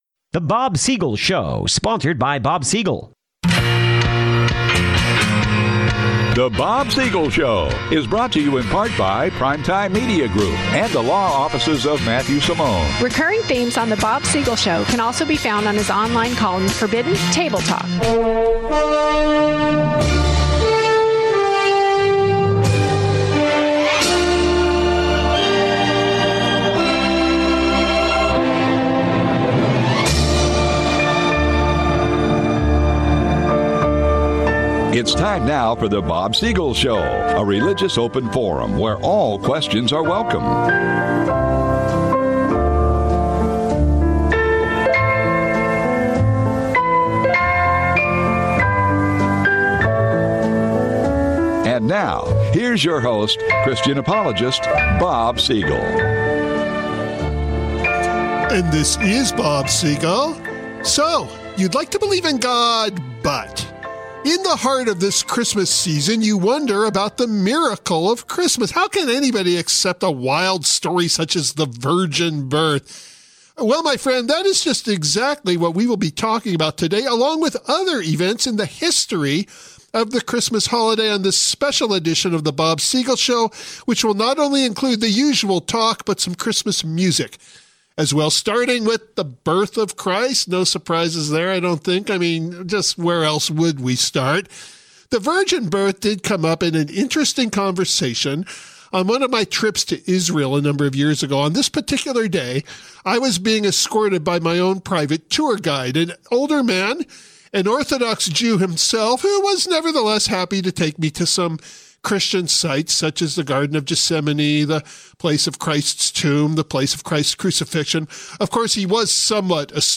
Repeat broadcast:
In this special program, a combination of talk and music